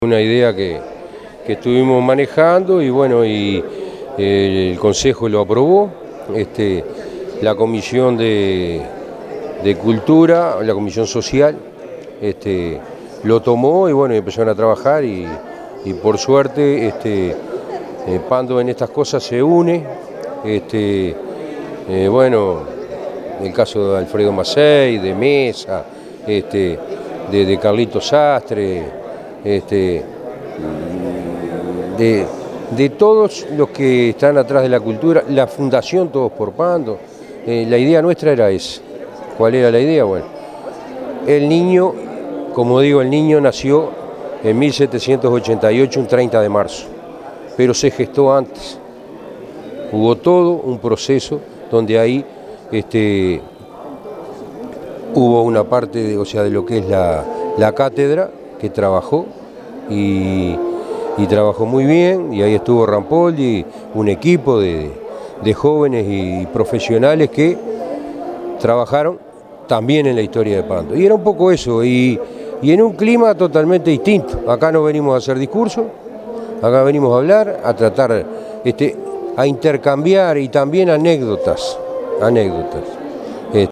alcides_perez_-_alcalde_del_municipio_de_pando.mp3